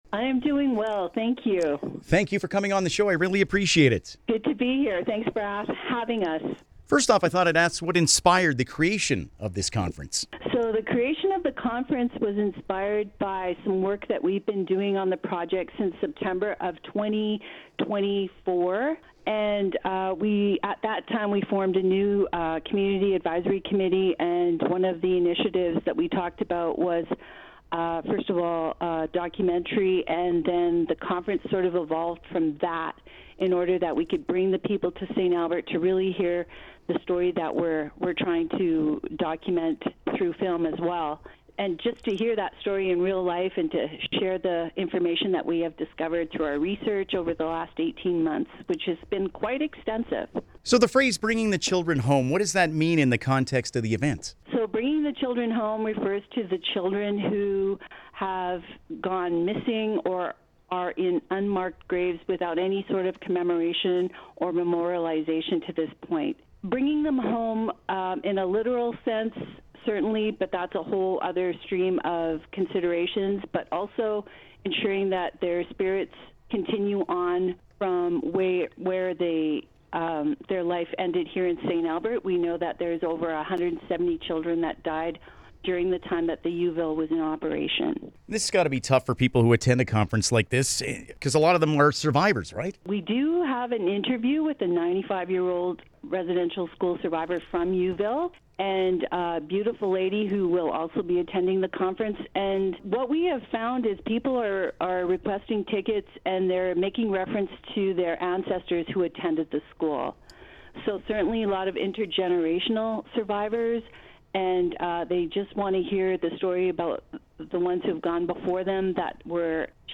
bringing-the-children-home-int.mp3